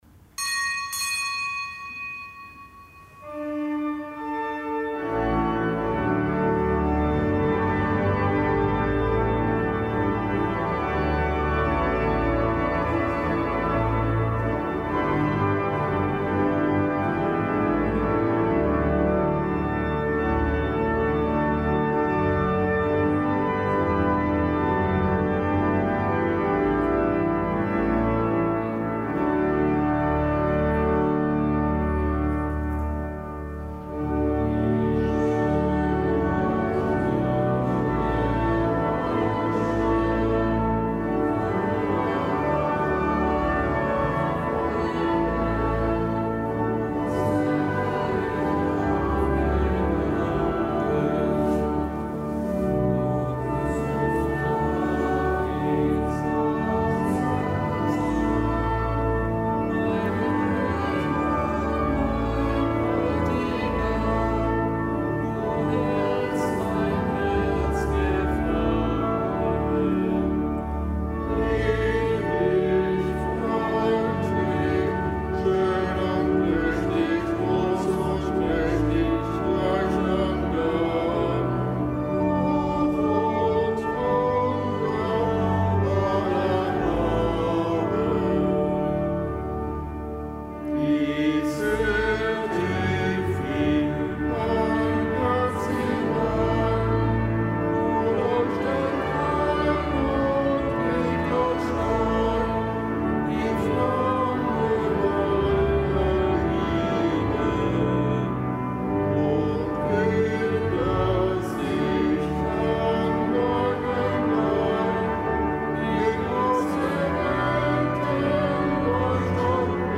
Kapitelsmesse am Gedenktag des Heiligen Heribert